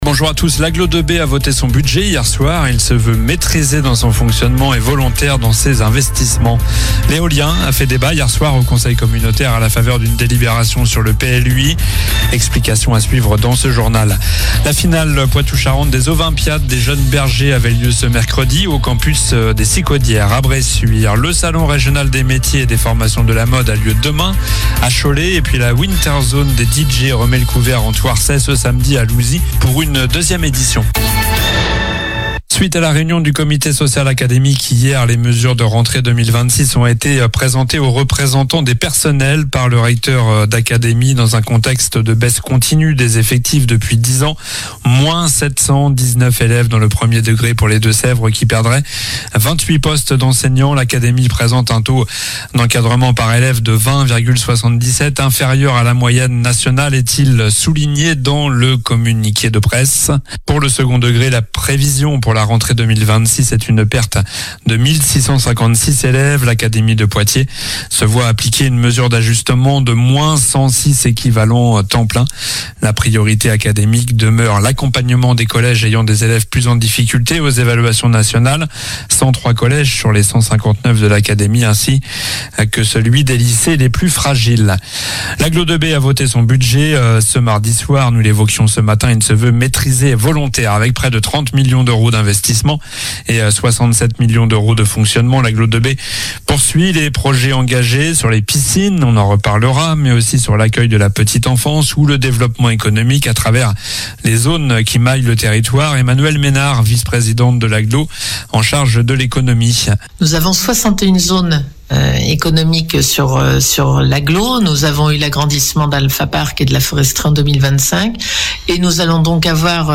infos locales